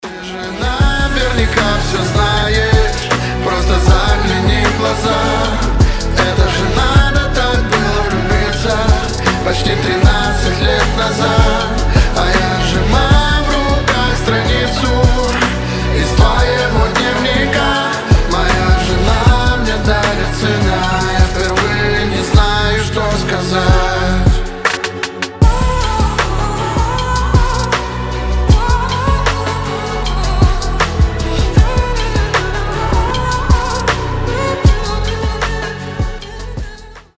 • Качество: 320, Stereo
мужской голос
душевные